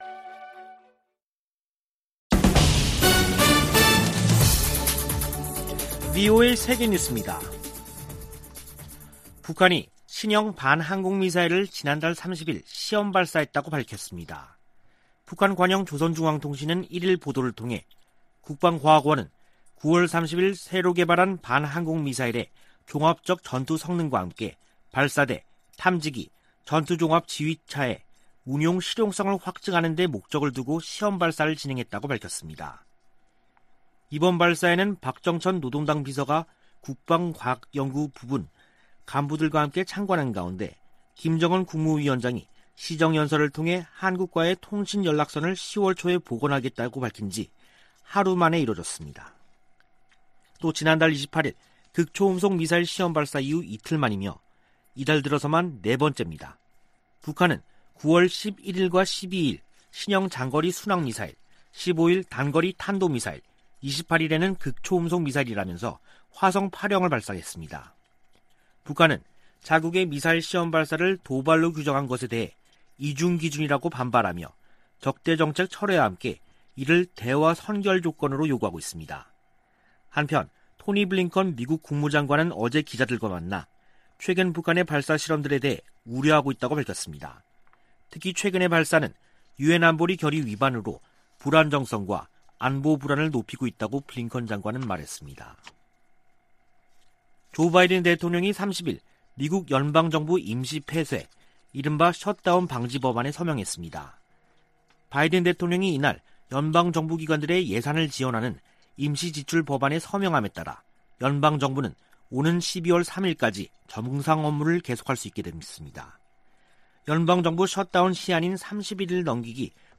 VOA 한국어 간판 뉴스 프로그램 '뉴스 투데이', 2021년 10월 1일 3부 방송입니다. 북한은 김정은 국무위원장이 남북 통신연락선 복원 의사를 밝힌 이튿날 신형 지대공 미사일을 시험발사했습니다. 토니 블링컨 미 국무장관은 북한의 극초음속 미사일 시험발사가 유엔 안보리 결의 위반이라고 지적했습니다. 미국의 전문가들은 김정은 위원장 시정연설에 대해 미국과 핵 문제를 협상할 의사가 없는 게 명백하다고 분석했습니다.